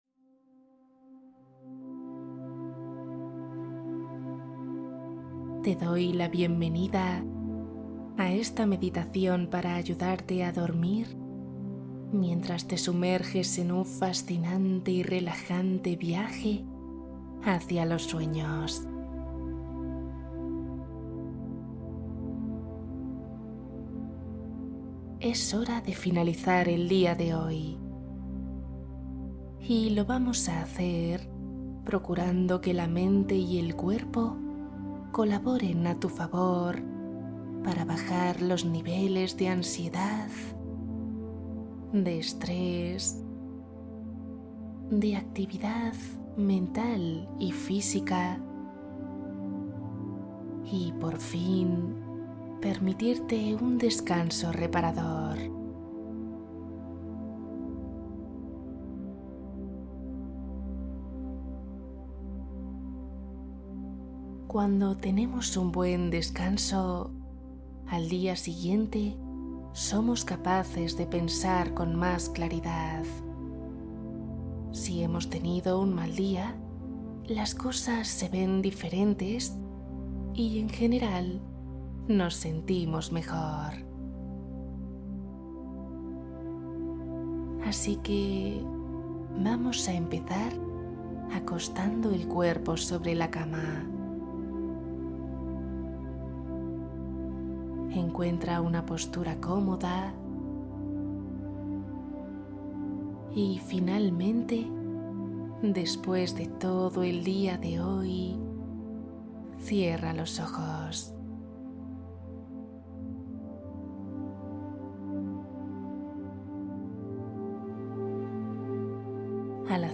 Viaje astral ✨ Meditación para dormir rápido y relajarte profundamente